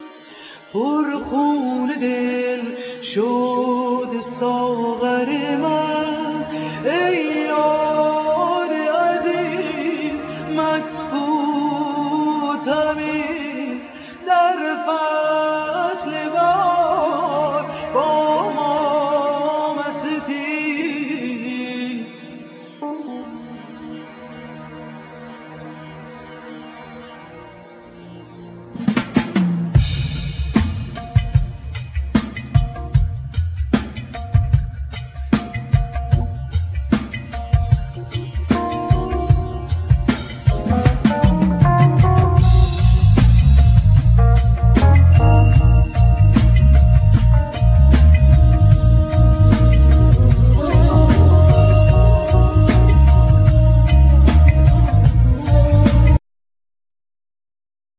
Vocals
Tabla
Tar, Setar, Kamanche, Ney
Cello